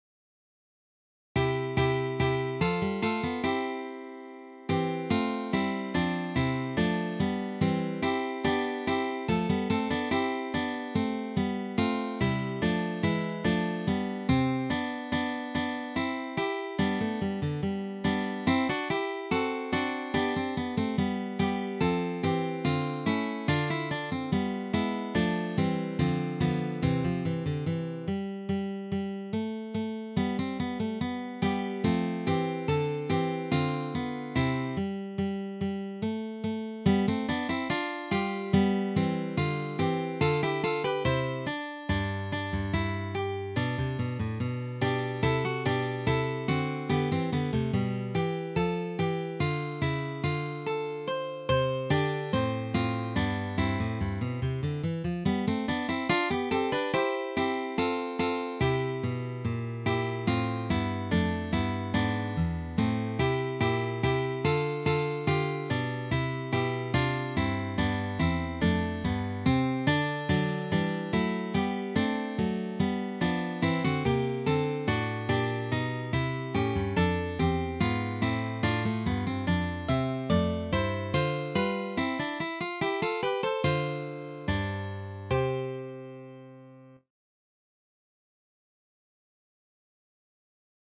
three guitars